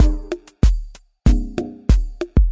Grungy Chord 95
标签： 95 bpm Hip Hop Loops Synth Loops 870.57 KB wav Key : Unknown
声道立体声